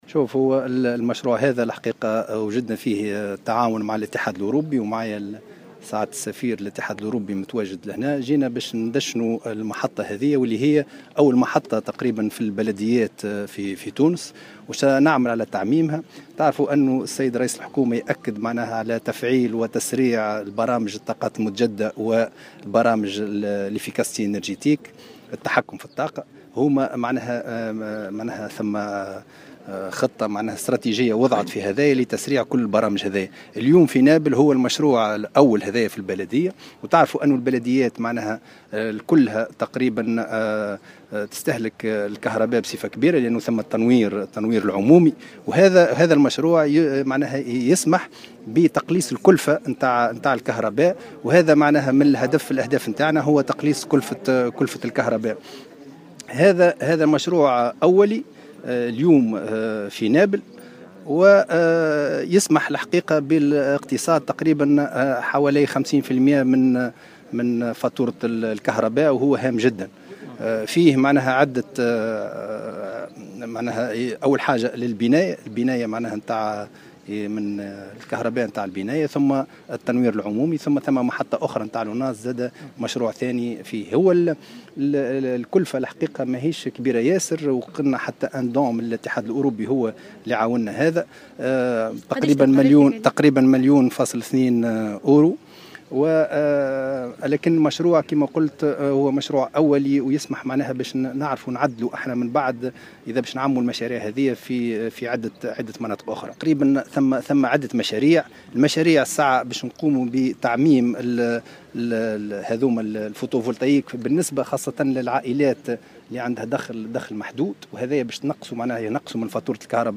وأشار الوزير خلال تدشين محطة نموذجية لإنتاج الطاقة الكهربائية بالطاقة الشمسية اليوم الأربعاء ببلدية نابل، إلى أن الحكومة قدمت عدة مقترحات لتنمية المناطق المنجمية مؤكدا تجاوب المتساكنين لأغلبها.